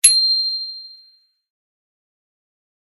bicycle-bell_18
bell bicycle bike clang contact ding glock glockenspiel sound effect free sound royalty free Sound Effects